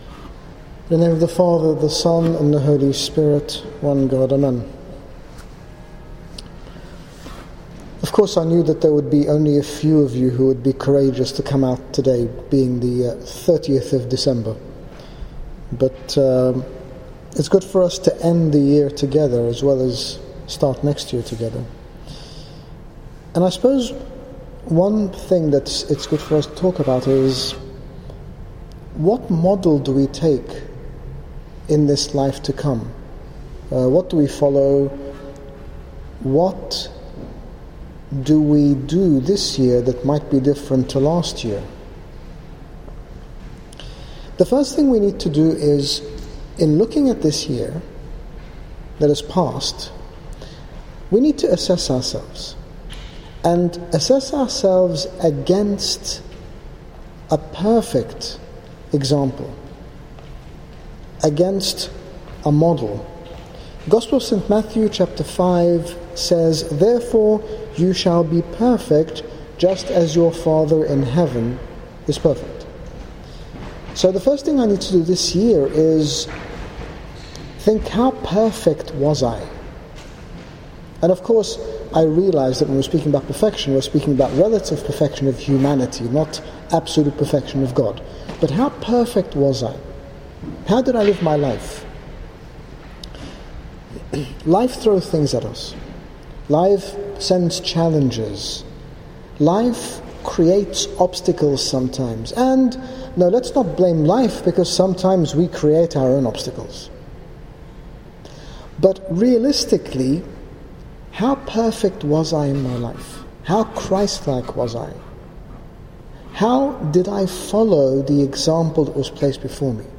In this talk, His Grace Bishop Angaelos, General Bishop of the Coptic Orthodox Church in the United Kingdom, speaks about following our Lord Jesus Christ as our role model in the New Year, highlighting the importance of regular self-assessment with regards to our spiritual journey. Download Audio Read more about Following our Lord in the New Year - HG Bishop Angaelos - Grapevine Fellowship Meeting